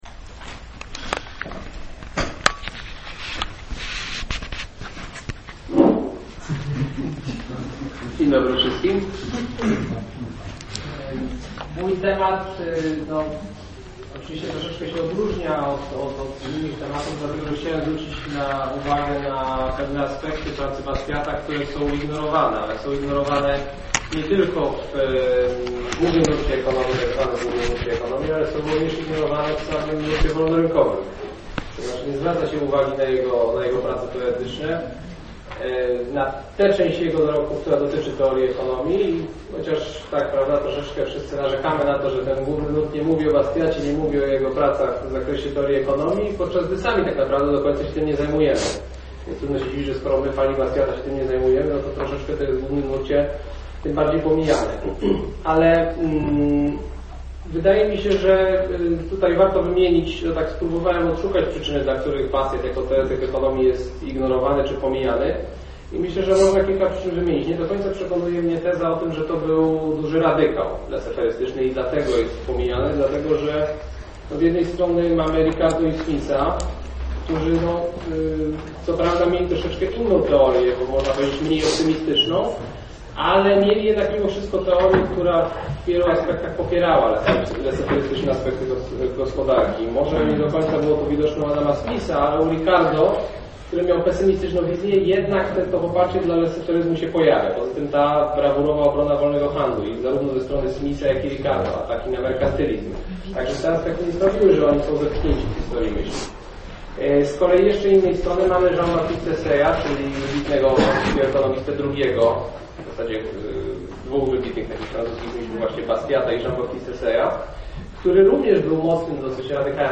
Wygłoszony on został podczas dwudniowej konferencji PAFERE LIBERTY WEEKEND, która odbyła się w dniach 19-20 września 2009 roku w Warszawie, a która poświęcona była w całości twórczości francuskiego ekonomisty Frederica Bastiata.